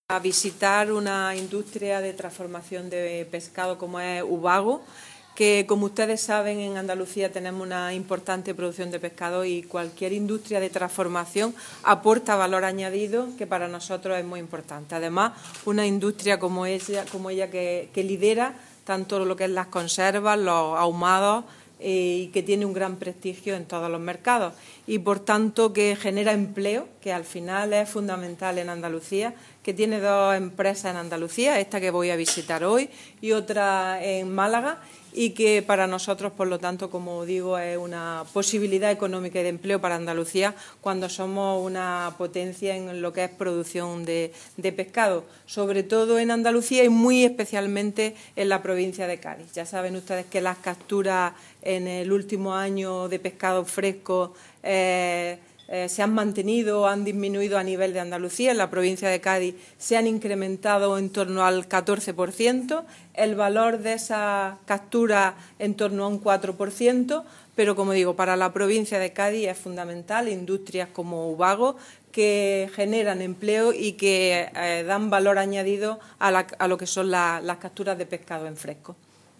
Declaraciones de Carmen Ortiz sobre Ubago y producción de pesca fresca de Cádiz